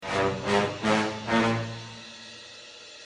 Play, download and share Godzilla horns original sound button!!!!
godzilla-horns.mp3